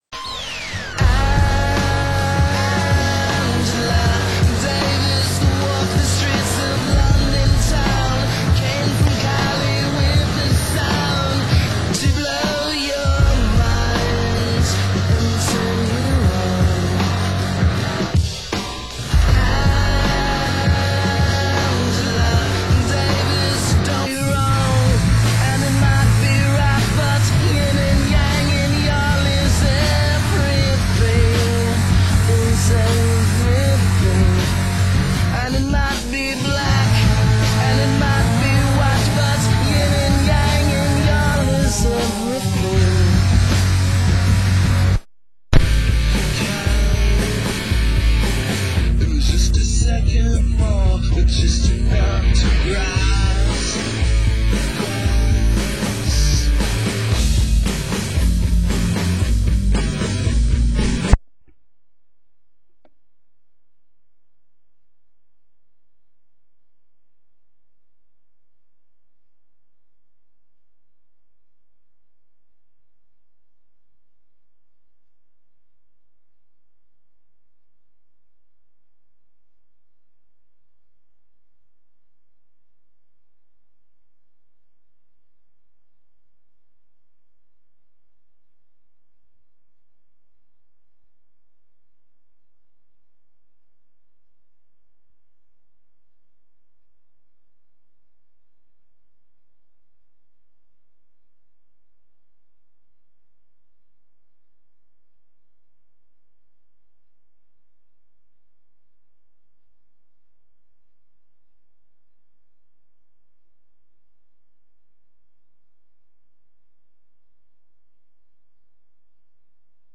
Genre: Indie Dance